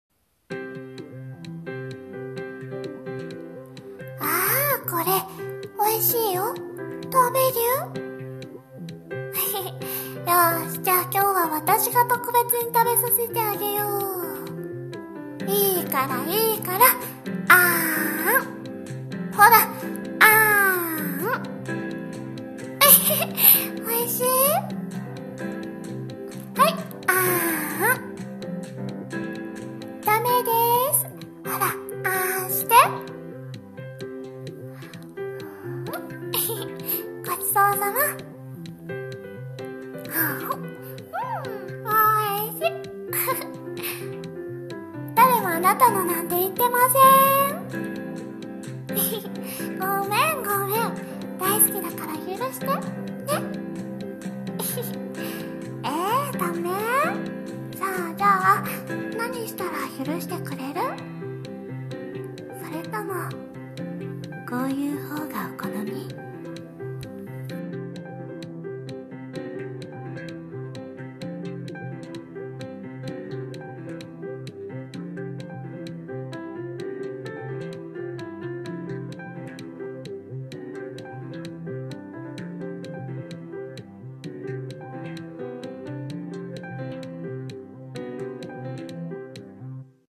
【声劇 台本】